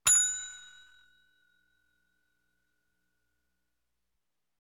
Ding Bell Sound Effect Free Download
Ding Bell